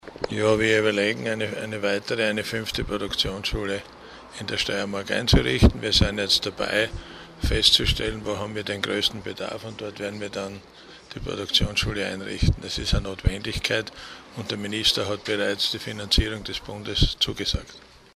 Zwei Jahre steirische Produktionsschulen - O-Töne
Soziallandesrat Siegfried Schrittwieser: